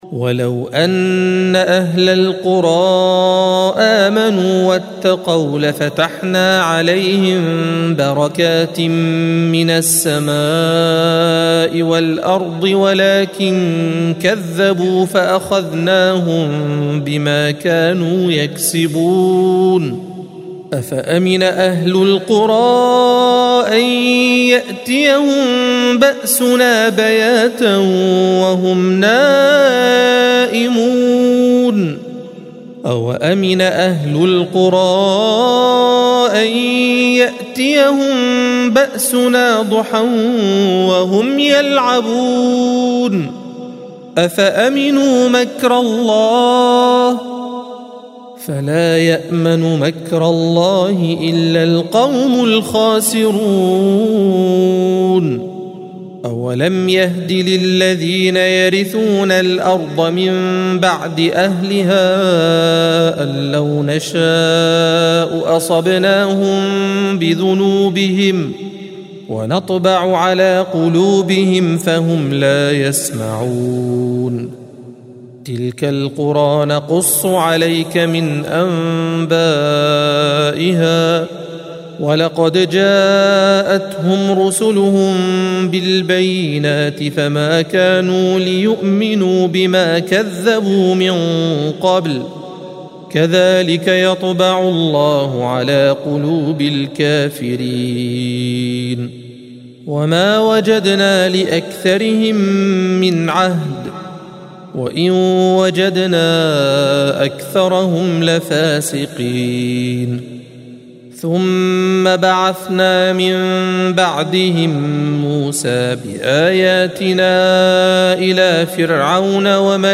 الصفحة 163 - القارئ